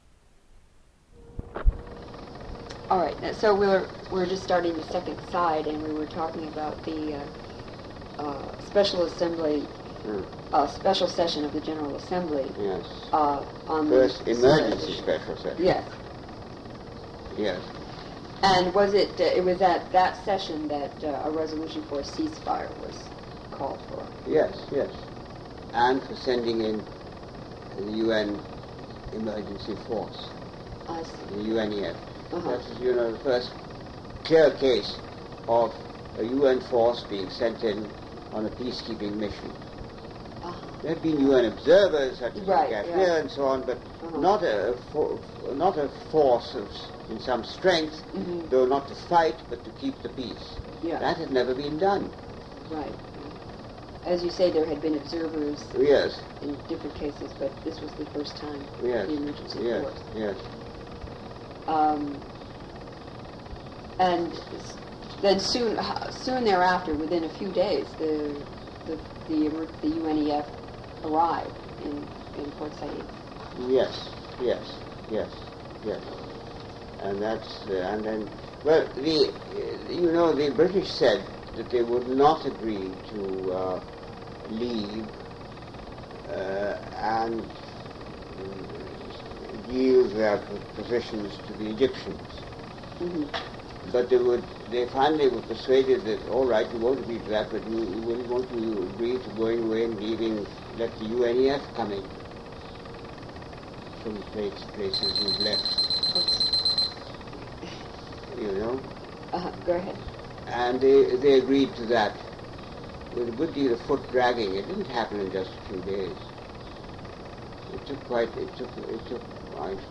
Interview with Arthur Lall /